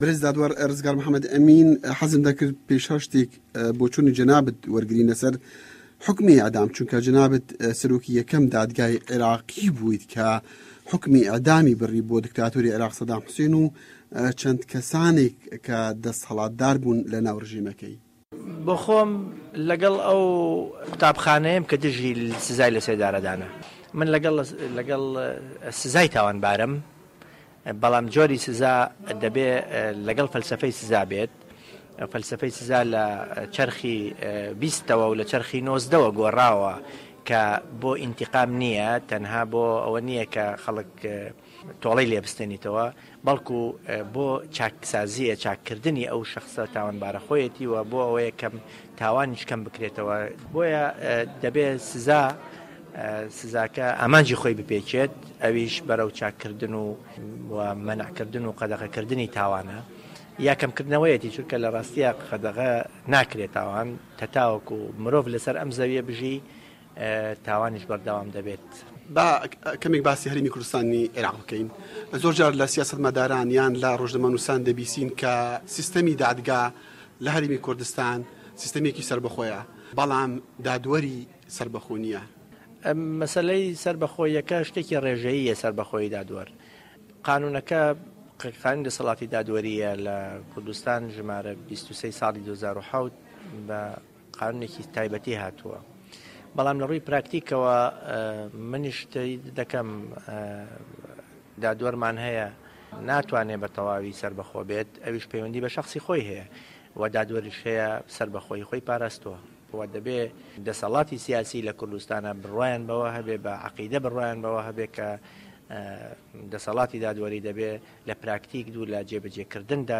وتووێژی ڕزگار ئه‌مین